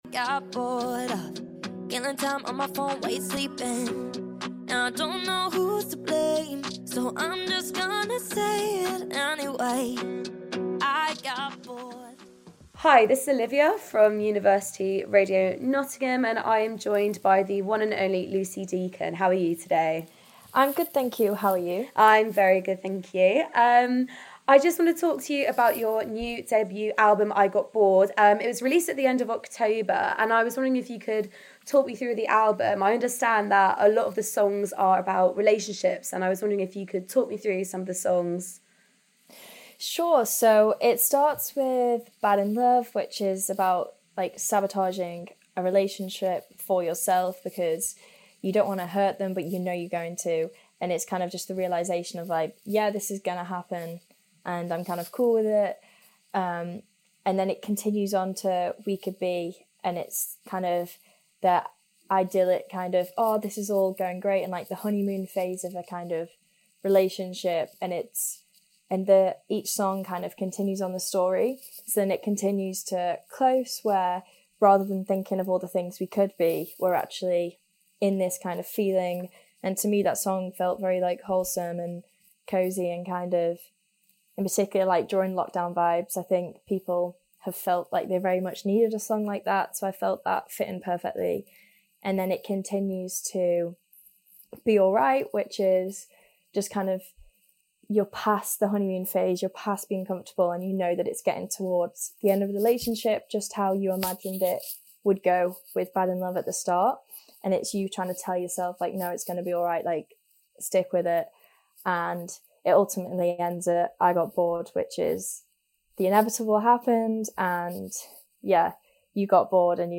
interviewed up and coming pop artist